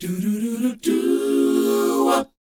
DOWOP D#AD.wav